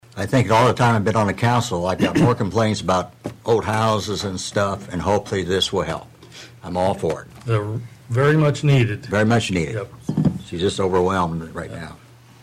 Councilmen Pat McCurdy and Gerald Brink voiced their full support of this addition to the city staff.